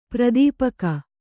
♪ pradīpaka